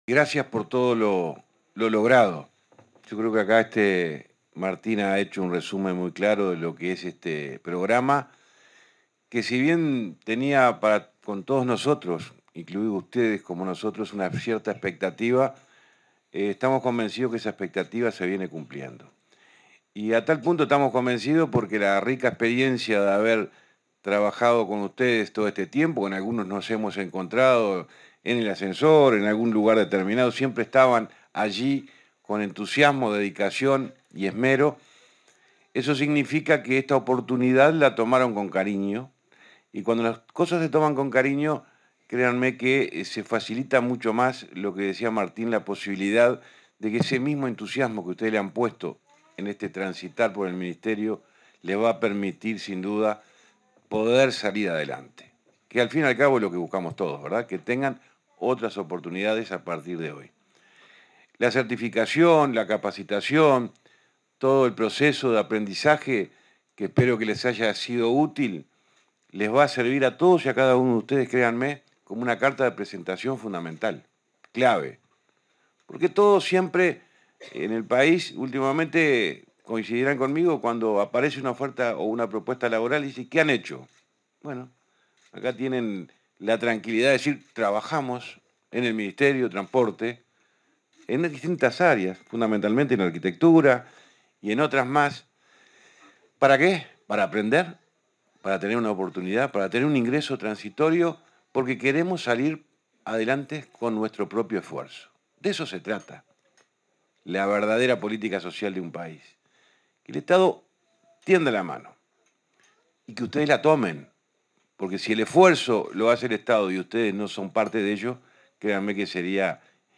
Palabras del ministro Transporte y Obras Públicas, José Luis Falero
Palabras del ministro Transporte y Obras Públicas, José Luis Falero 01/12/2023 Compartir Facebook X Copiar enlace WhatsApp LinkedIn En el marco del cierre del programa Accesos edición 2023, se expresó, este 1 de diciembre, el ministro de Transporte y Obras Públicas, José Luis Falero.